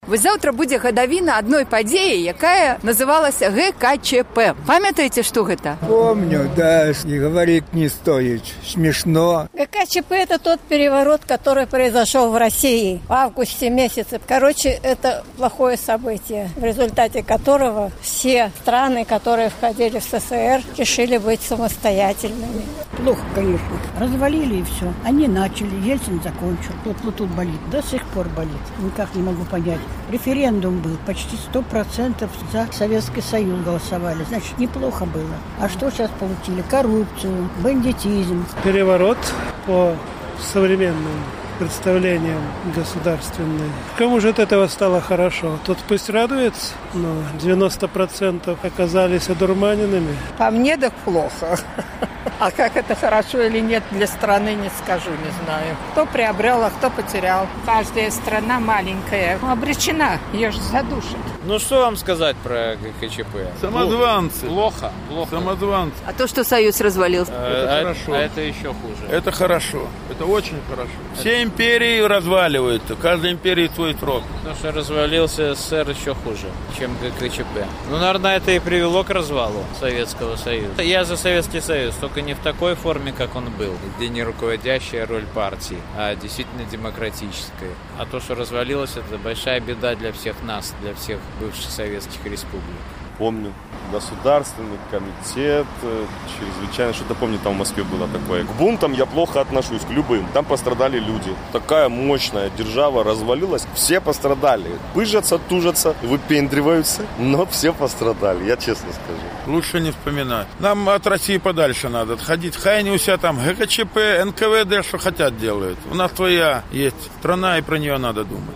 Людзі сталага веку пра ГКЧП